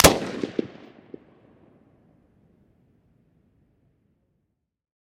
На этой странице собраны реалистичные звуки стрельбы из автомата в высоком качестве.
Звук выстрела из полуавтоматической винтовки AR-15